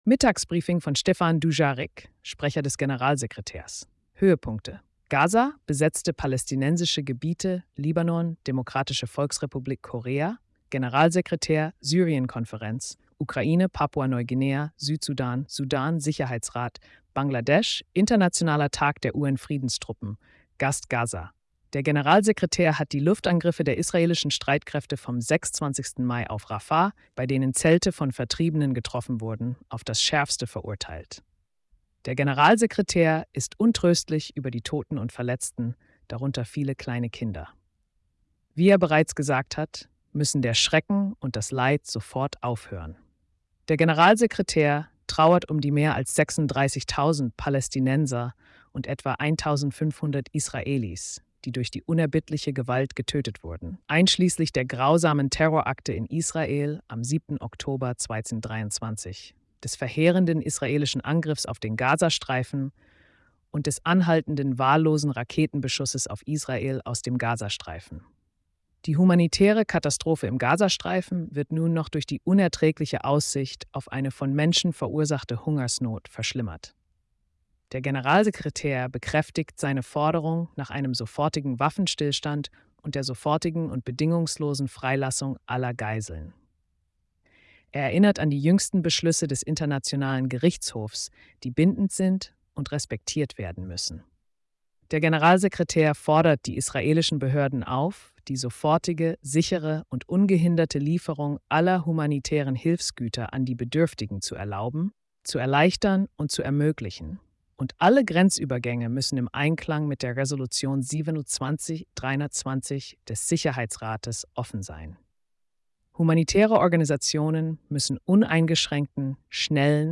Mittagsbriefing von Stéphane Dujarric, Sprecher des Generalsekretärs.